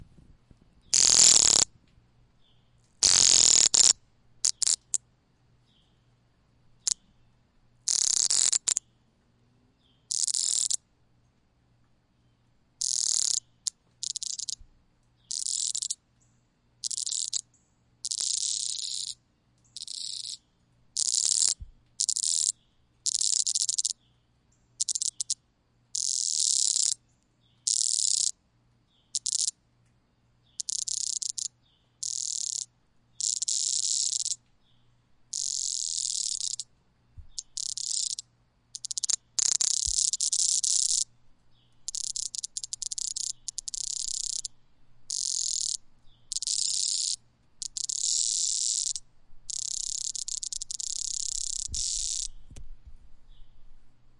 傍晚的鸟儿和蝉
描述：蝉在晚上与当地鸟类弗吉尼亚州
Tag: 昆虫 性质 现场记录